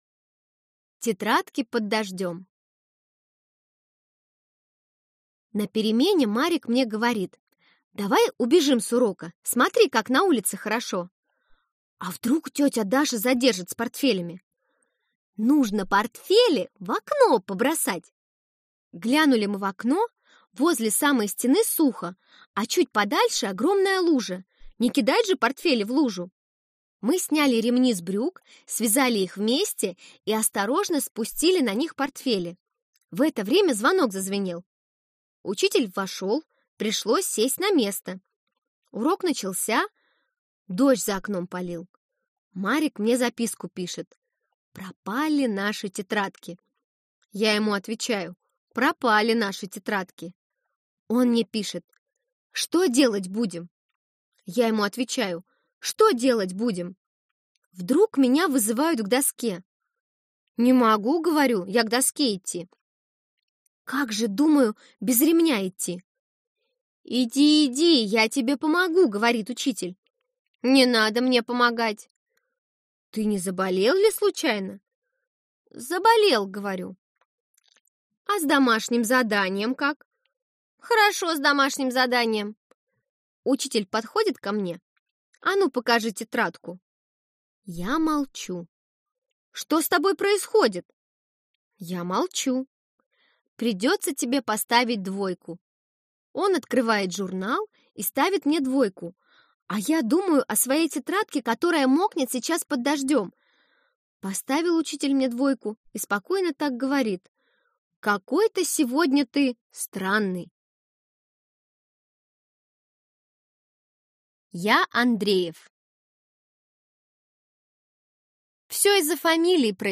Аудиокнига Тетрадки под дождём | Библиотека аудиокниг